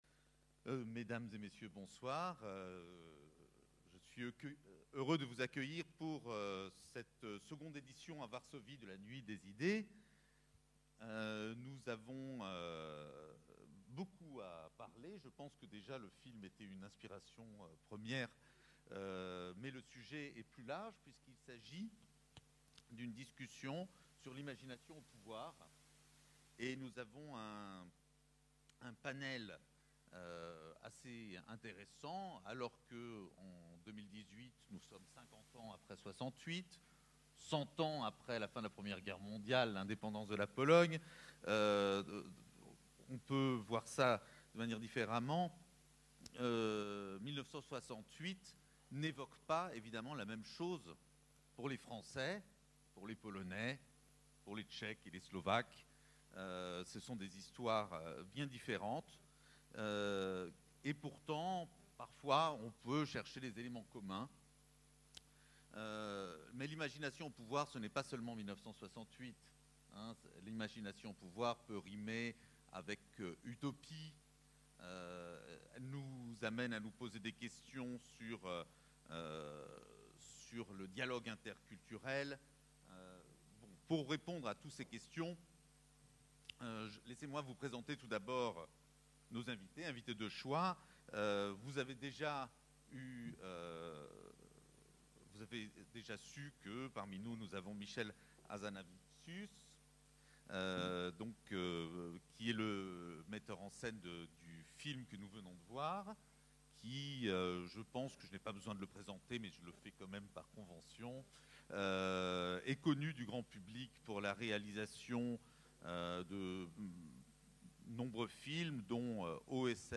Débat sur le thème de l’ « imagination au pouvoir », ayant eu lieu lors de la Nuit des Idées à Varsovie, le 25 janvier 2018, suite à la diffusion du film Le Redoutable sur le cinéaste Jean Godard.
Michel Hazanavicius, cinéaste et metteur en scène du film Le Redoutable. Pierre Lévy, Ambassadeur de France en Pologne.